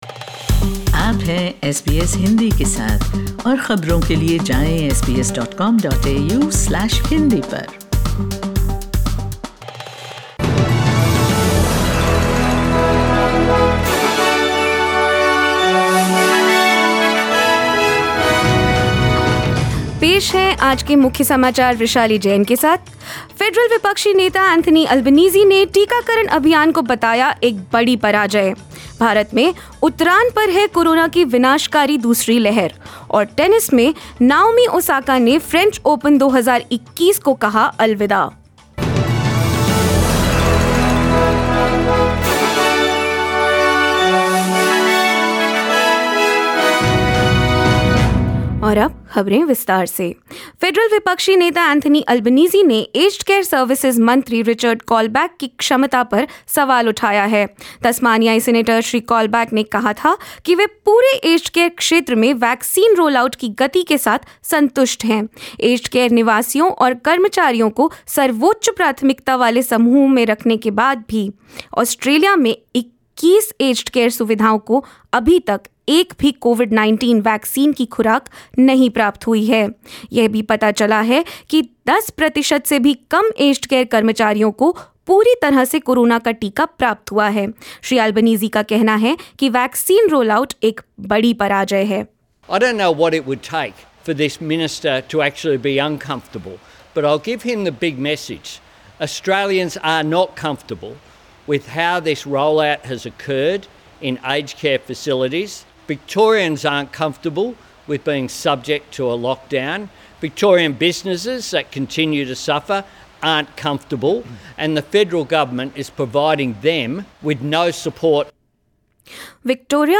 In this latest SBS Hindi News bulletin of Australia and India: Federal opposition leader Anthony Albanese termed the Australian vaccination program 'a debacle'; India's second wave of Covid-19 appears to be weakening and more. 01/06/21